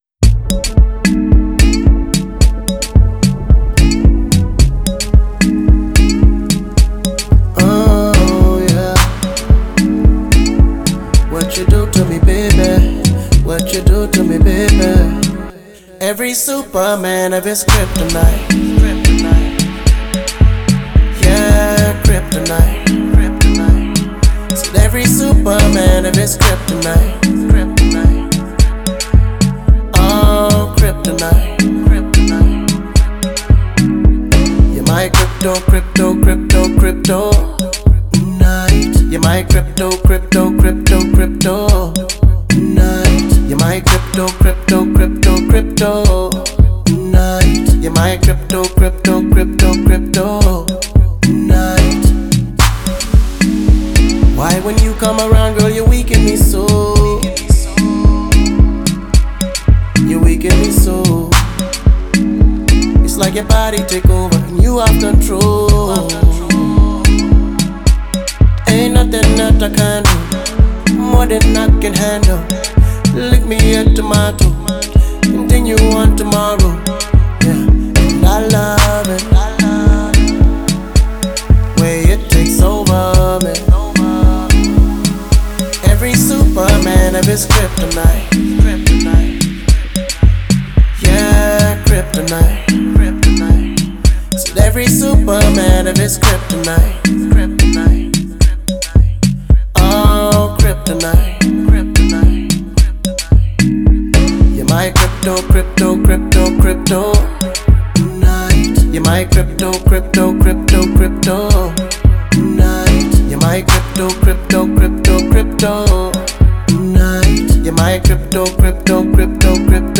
это песня в жанре R&B